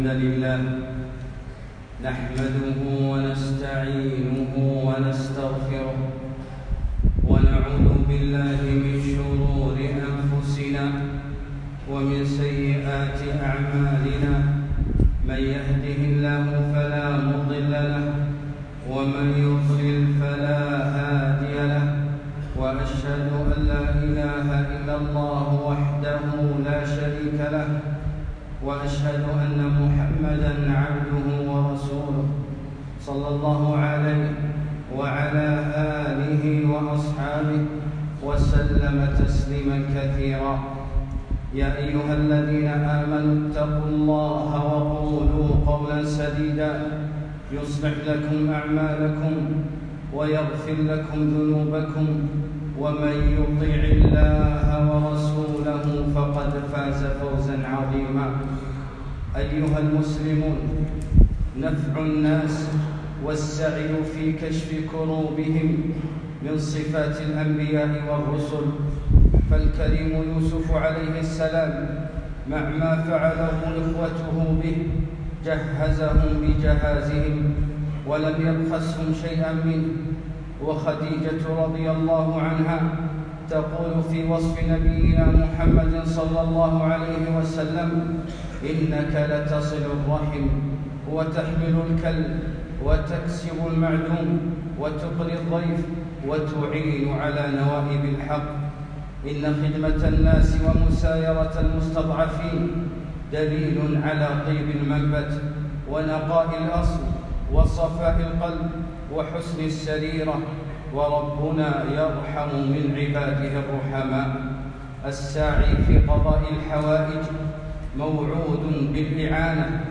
خطبة - نفع الناس - دروس الكويت